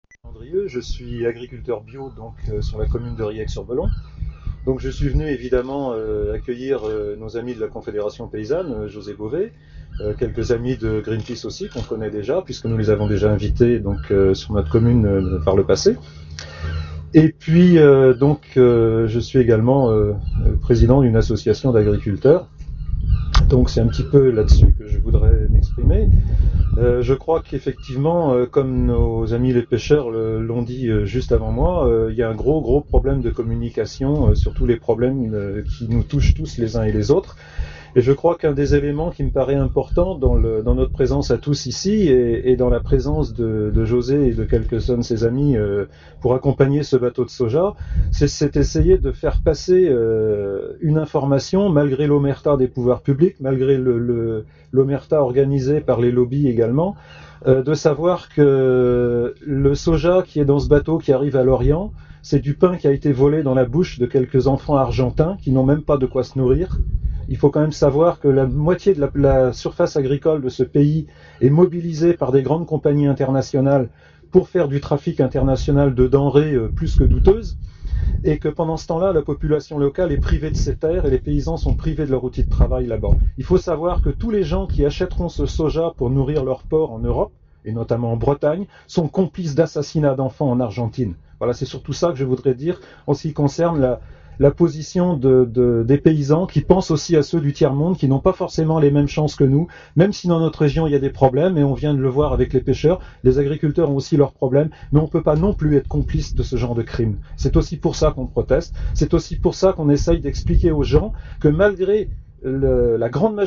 Parmi tous ces manifestants un agriculteur biologique témoigne.
SOUTIEN AGRICULTEUR BIO BRETON - mp3 - 1750ko